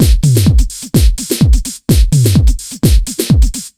127BEAT8 5-R.wav